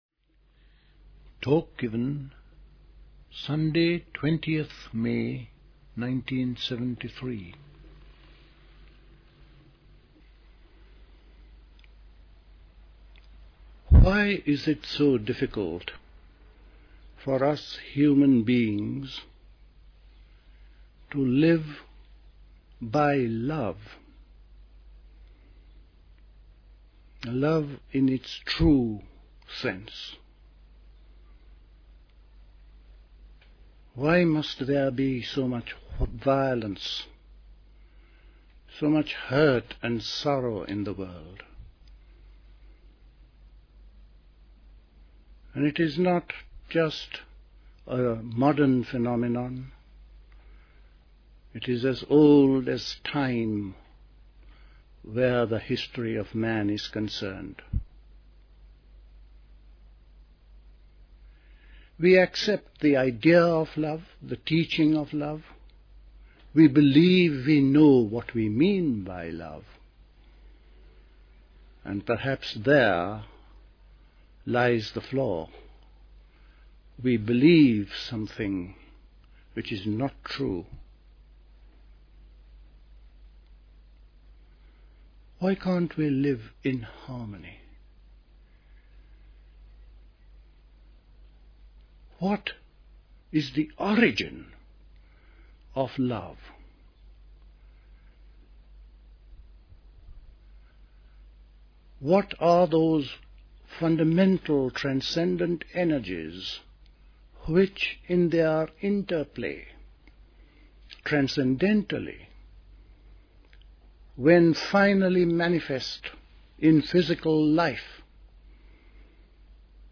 Dilkusha, Forest Hill, London